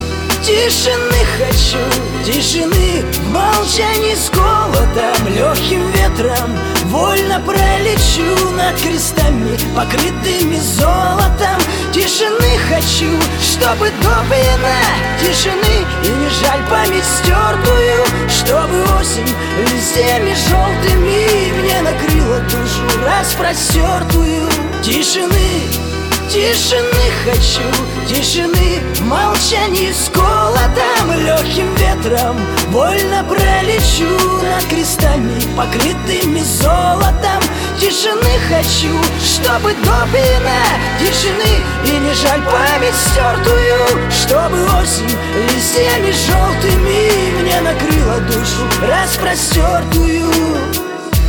• Качество: 320, Stereo
грустные
печальные
сиплый голос